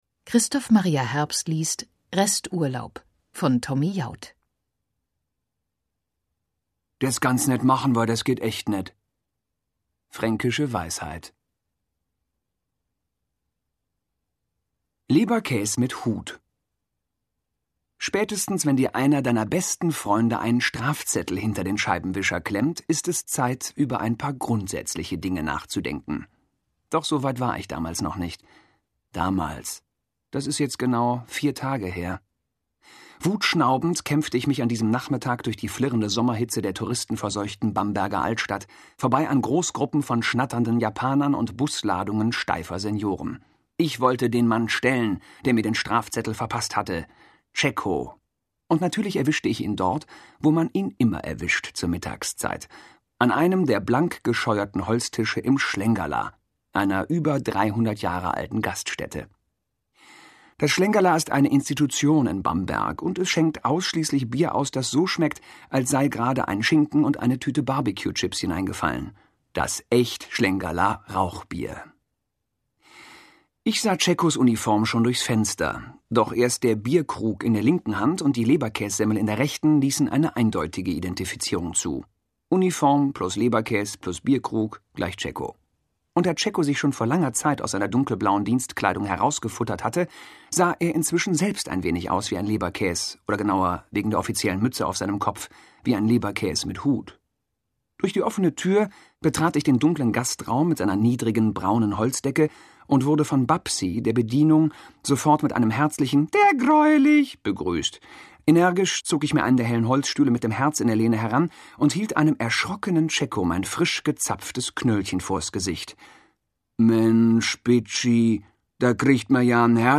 Resturlaub (DAISY Edition) Tommy Jaud (Autor) Christoph Maria Herbst (Sprecher) Audio-CD 2008 | 1.